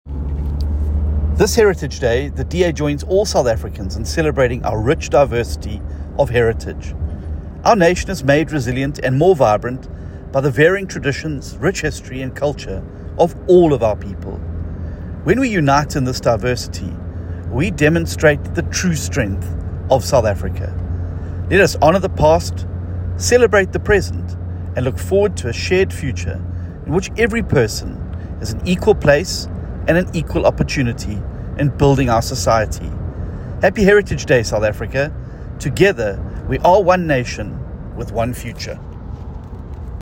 soundbite by John Steenhuisen MP.